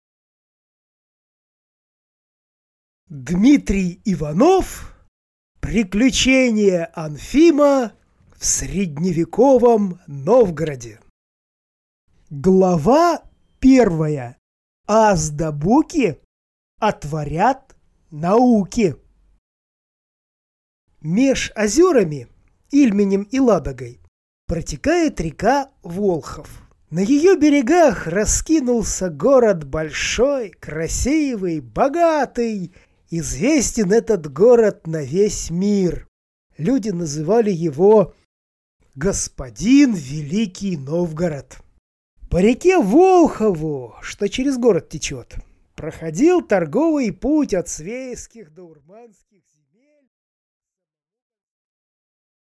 Аудиокнига Приключения Онфима в средневековом Новгороде | Библиотека аудиокниг